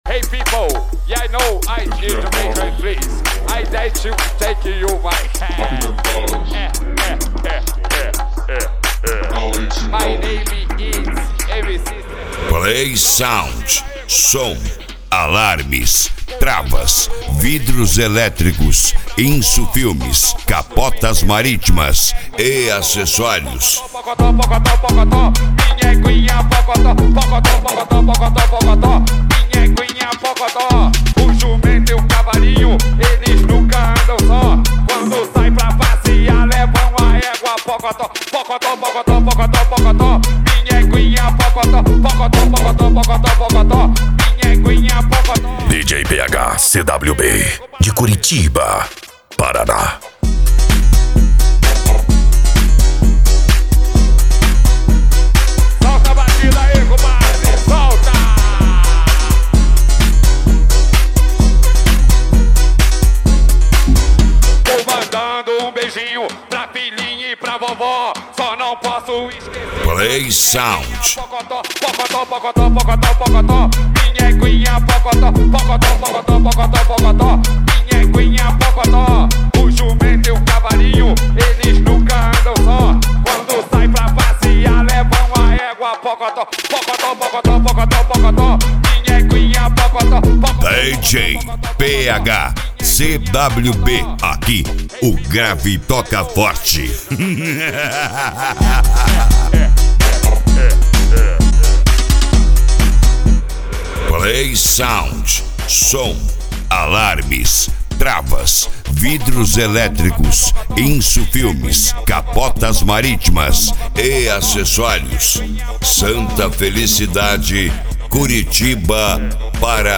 Eletronica
Funk
PANCADÃO
SERTANEJO